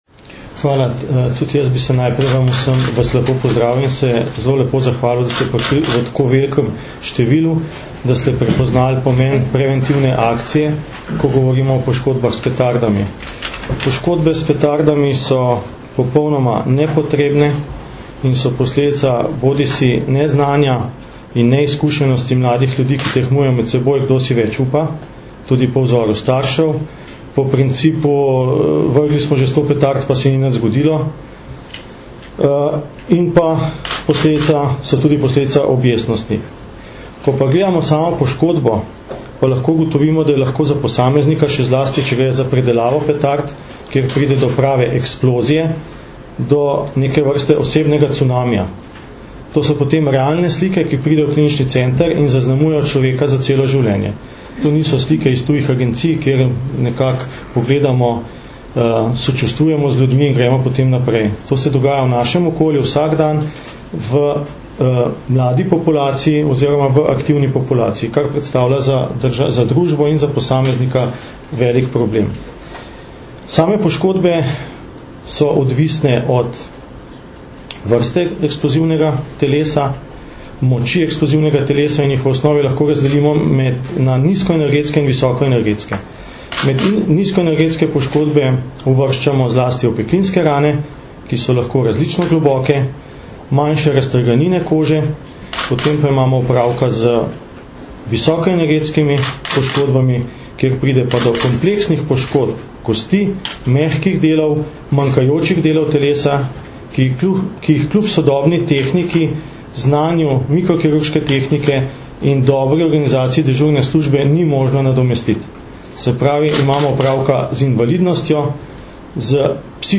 Policija - Za varne praznike brez poškodb s pirotehničnimi izdelki - informacija z novinarske konference
Zvočni posnetek izjave